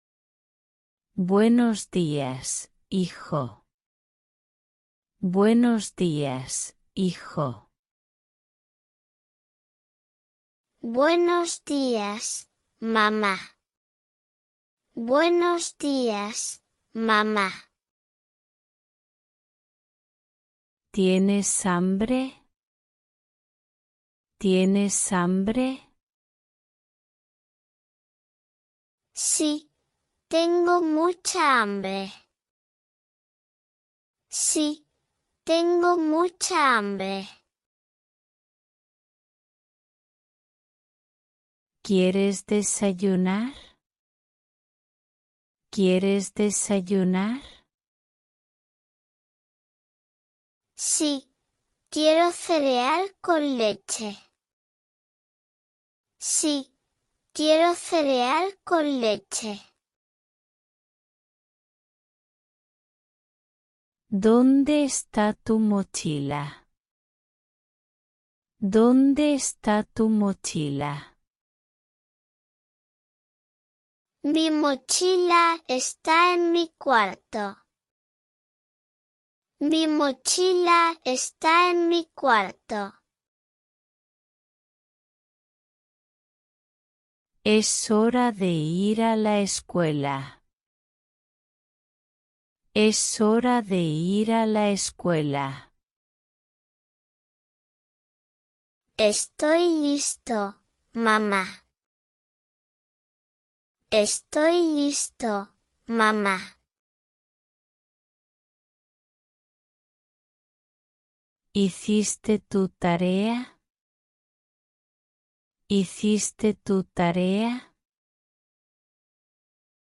100 Mother & Son Conversations in Spanish After School